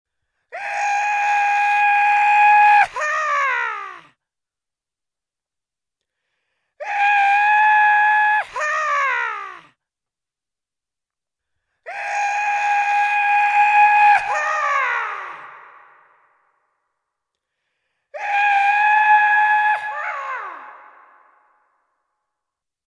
Звуки ковбоев, дикого запада
• Качество: высокое